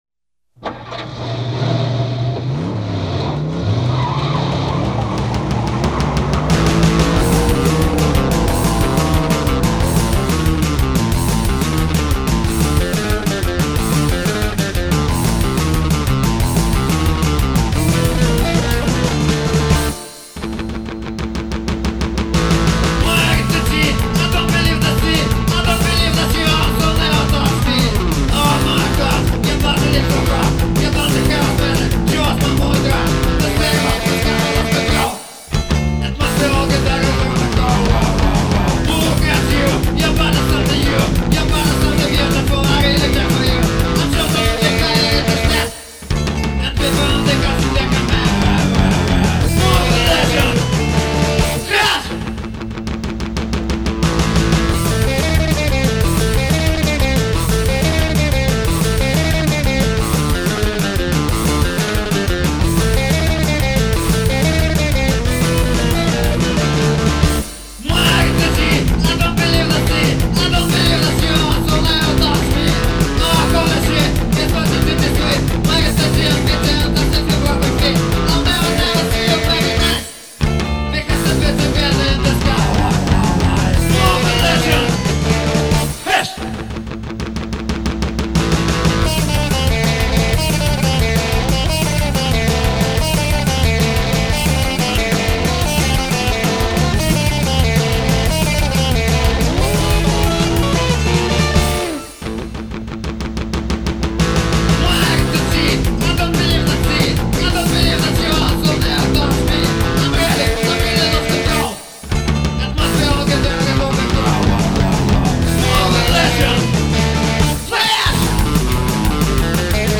Дебютный EP сайкобилли монстров из Витебска.